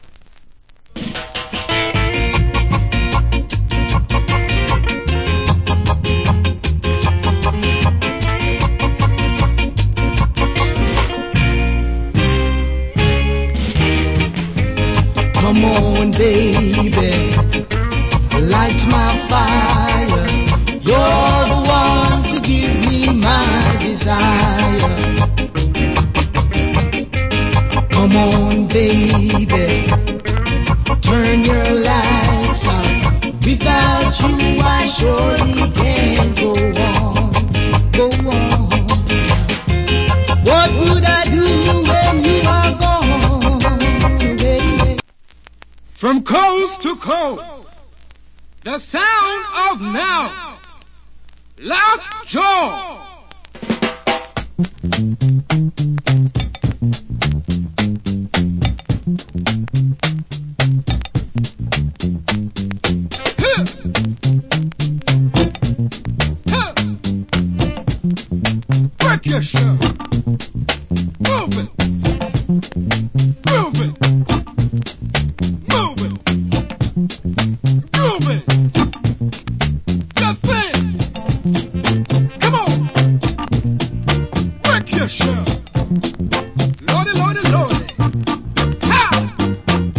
Killer Early Reggae Vocal.